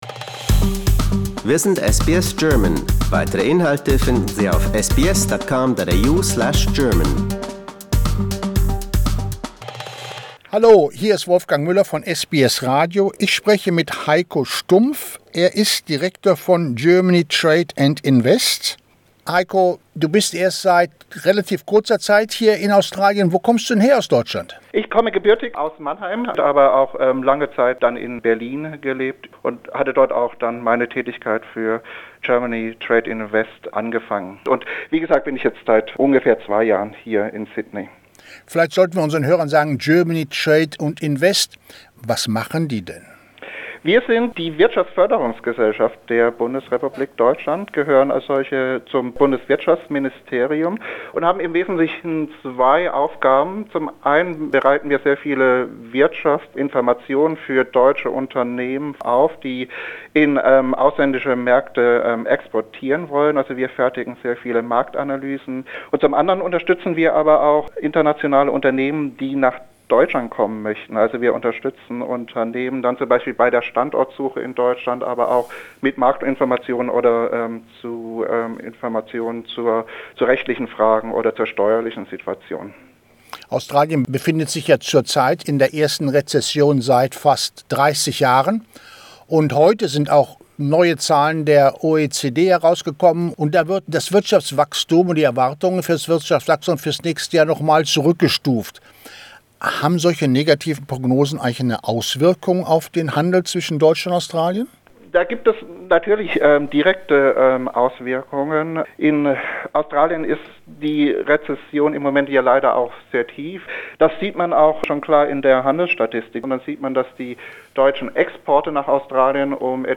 How does that impact on its trade with Germany? An expert has an answer.